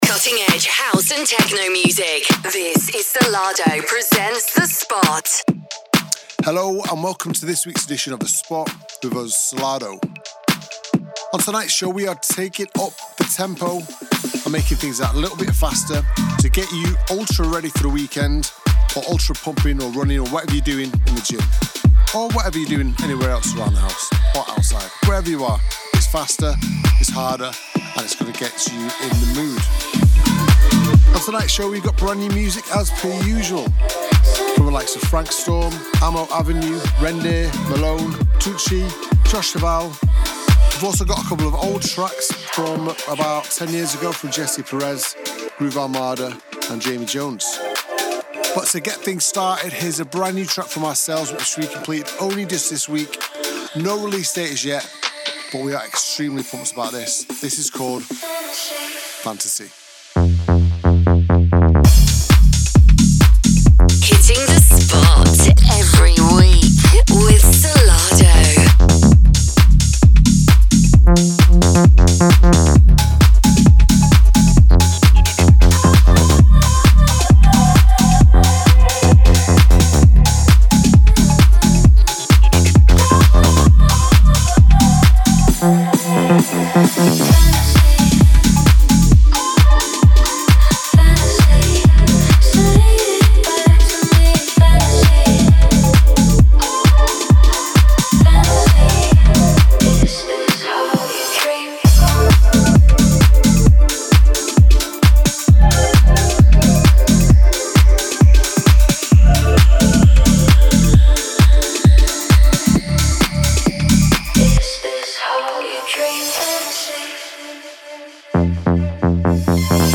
music DJ Mix in MP3 format
Genre: Tech House